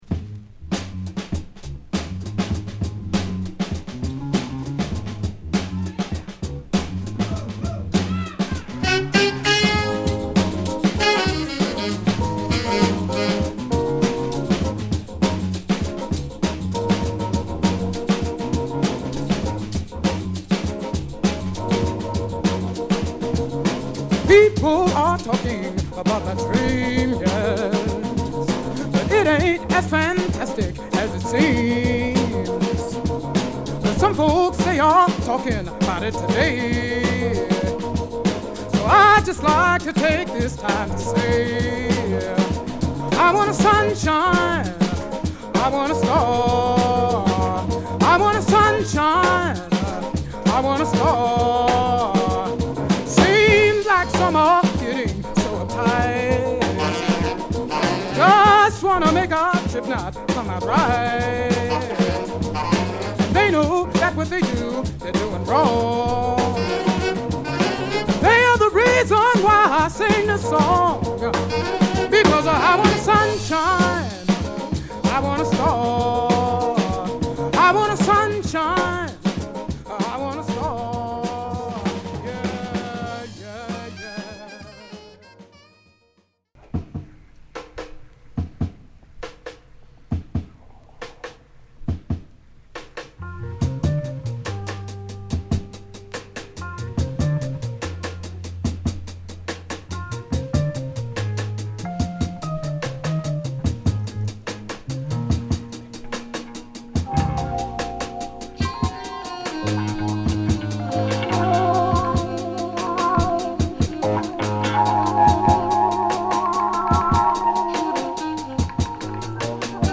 ベルギー産の激レア・ジャズファンク／レア・グルーヴ！